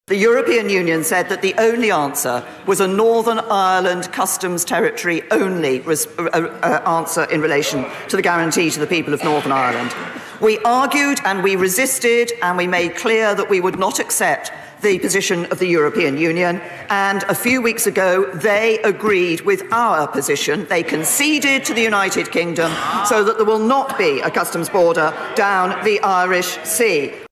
Theresa May has told the House of Commons that the EU gave in to Britain’s proposals for avoiding a hard border…………